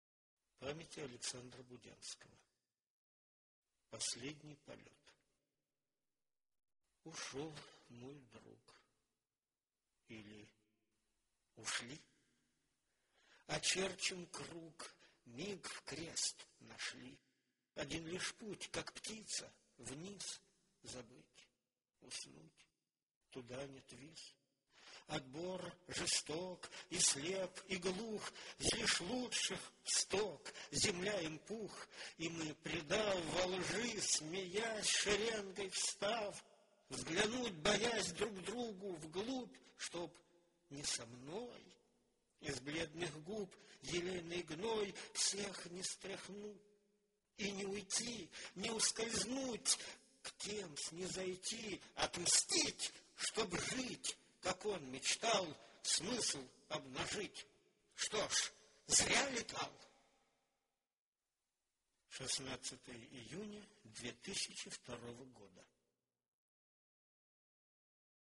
"Последний полет" - стихотворение